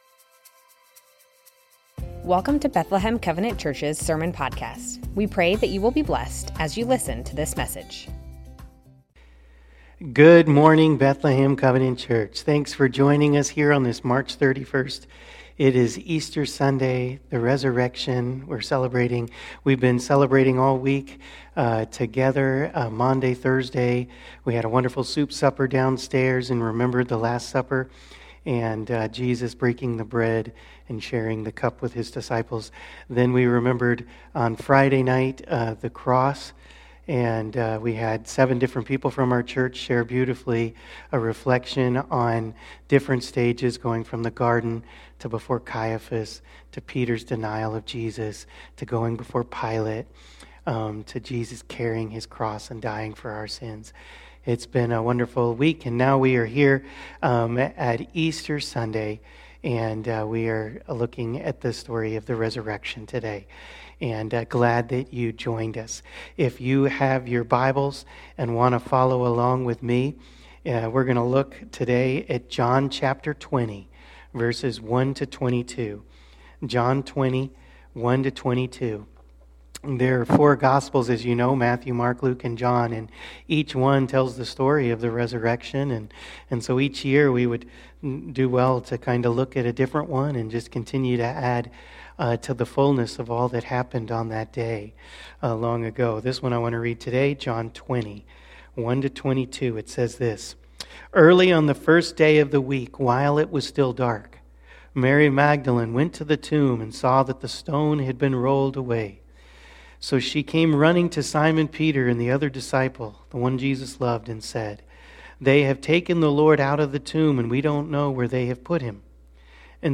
Bethlehem Covenant Church Sermons The Lord is risen...and it changes EVERYTHING!